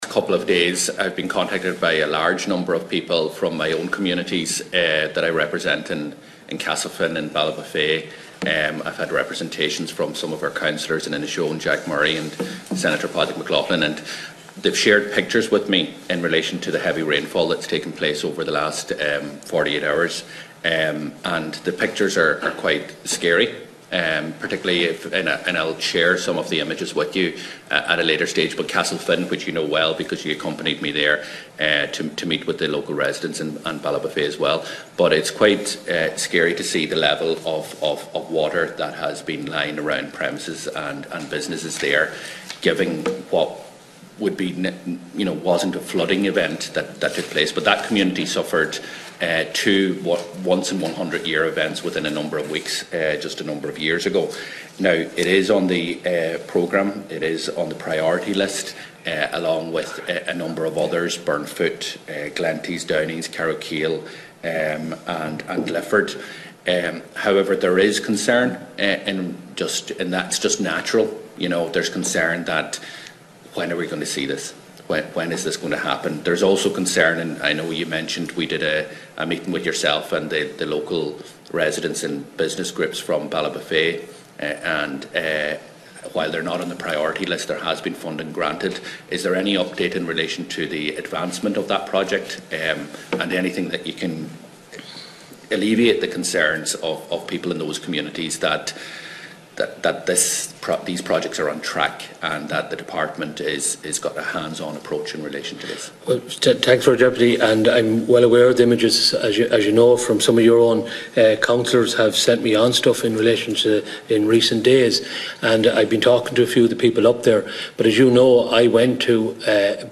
Its in response to Donegal Deputy Pearse Doherty who told Minister Kevin Boxer Moran during an Oireachtas Finance Committee that little progress was being made to deliver long promised flood and coastal erosion schemes in a number of areas in the county.
Minister Boxer Moran told Deputy Doherty that public representatives need to be putting more pressure on Donegal County Council to expedite the process: